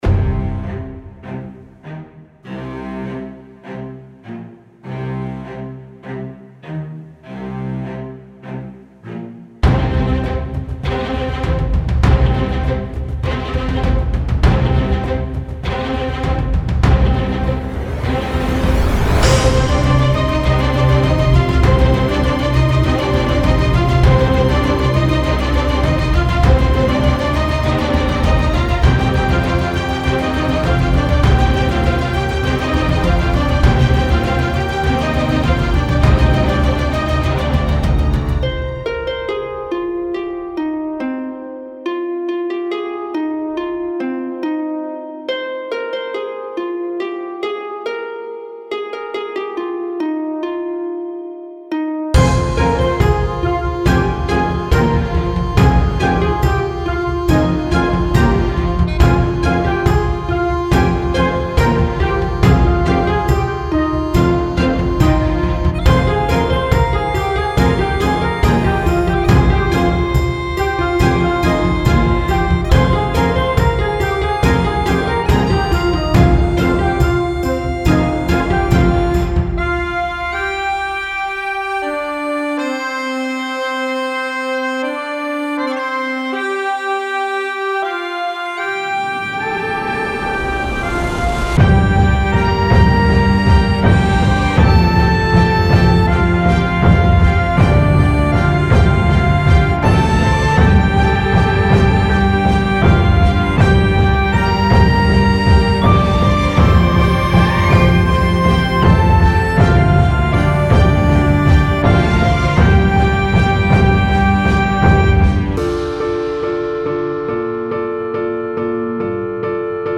Genre:Action
デモサウンドはコチラ↓
Tempo/Bpm 70-120